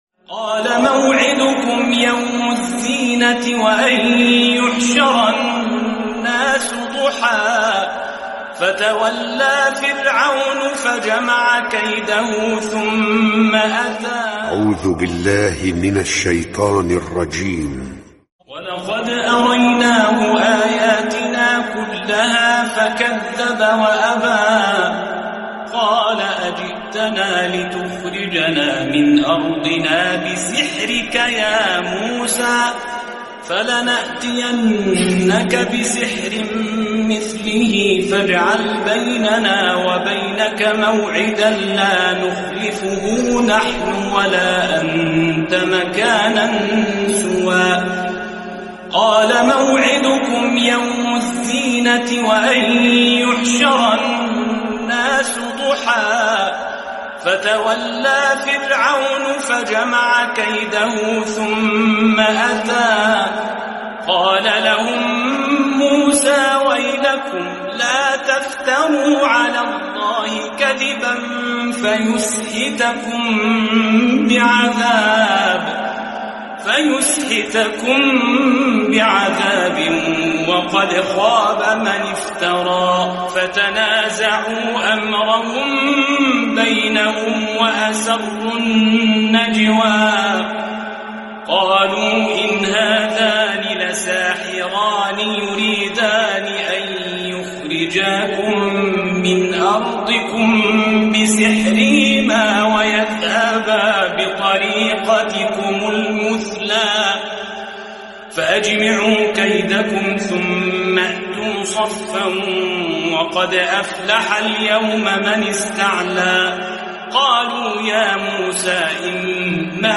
Quran recitations